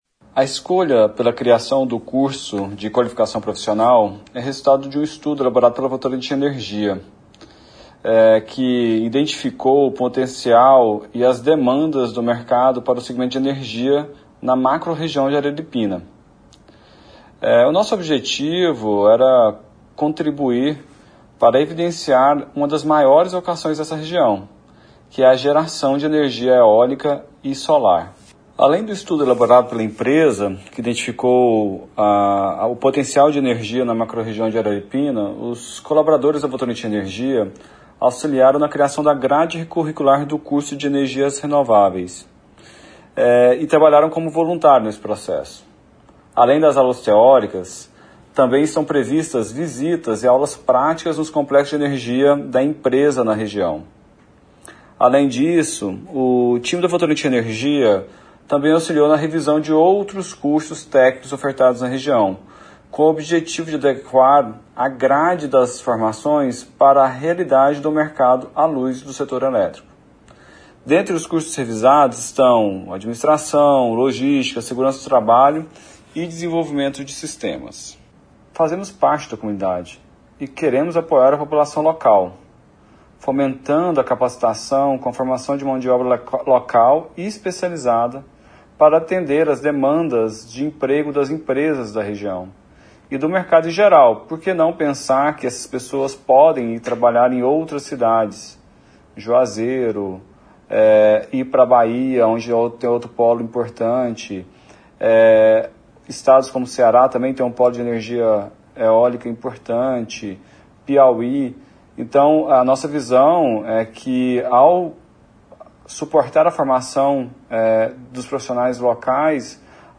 falou nessa segunda-feira no programa Araripina Urgente da Rádio Arari FM